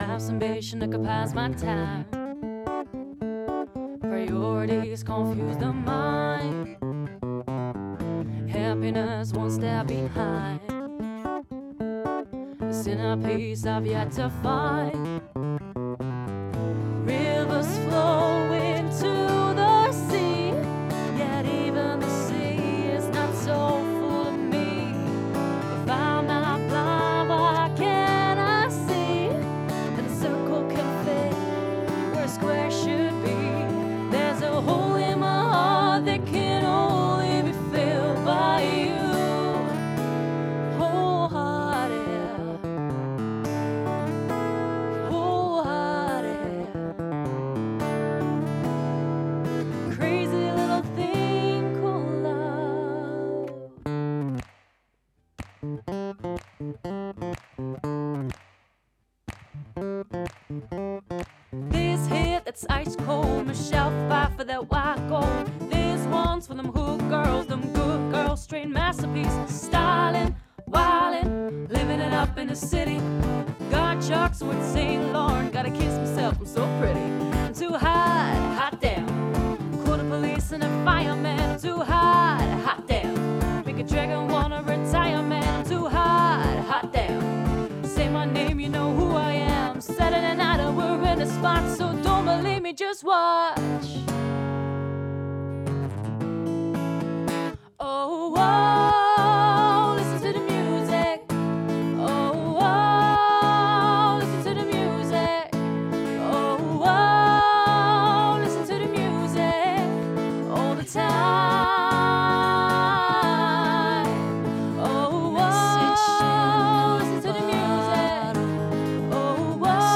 akoestisch duo